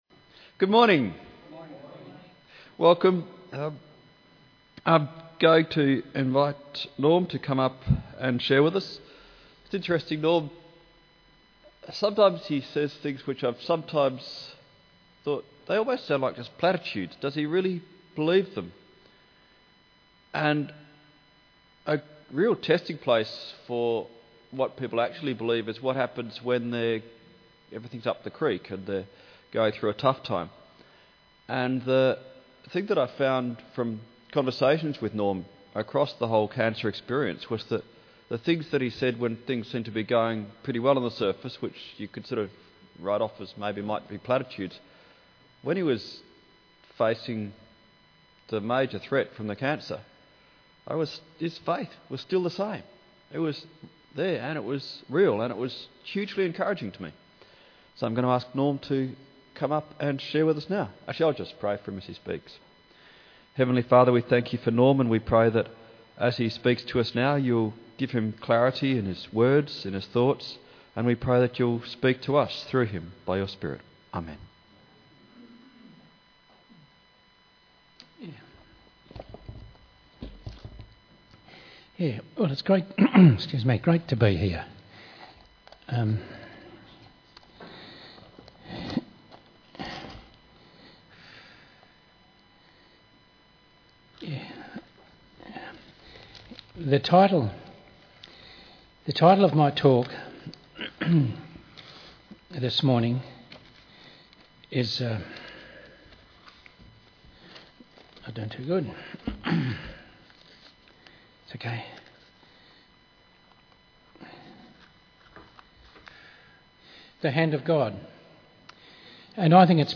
talk at the Men’s Breakfast